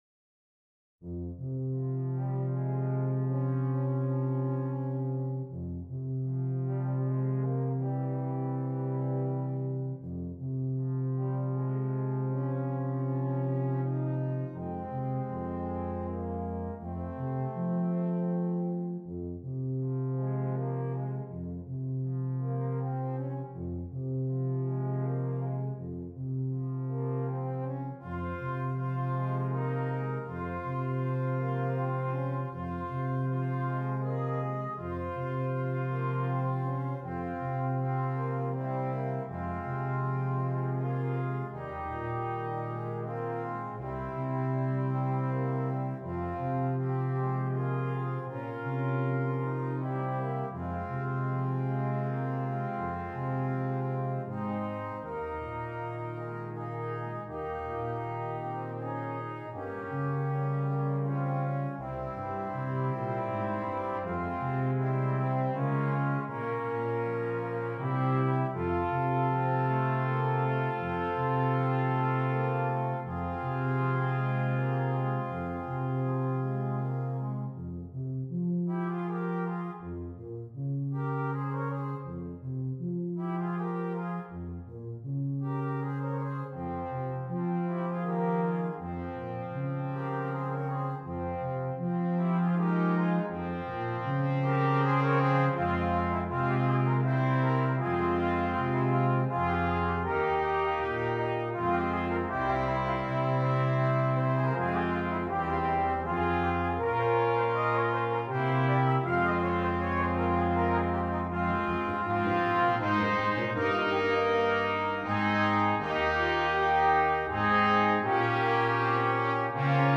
Brass Quintet
Traditional Carol